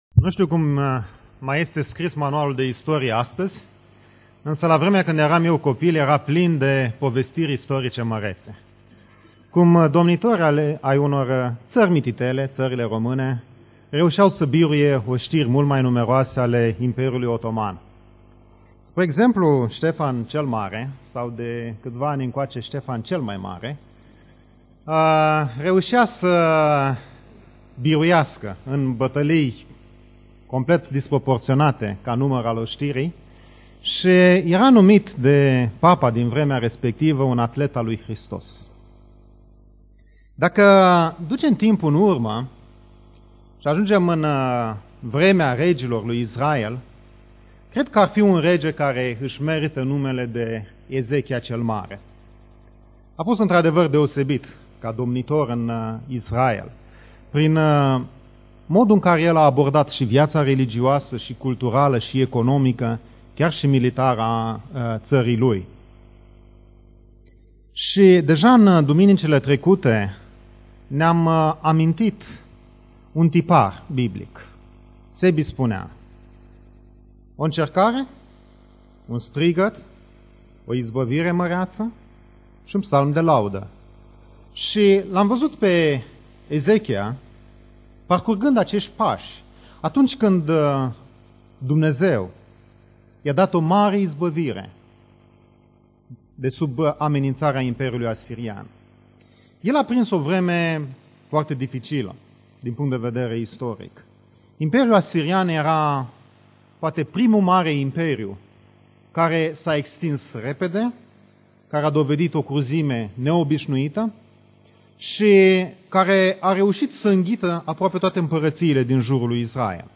Predica Exegeza - Isaia 39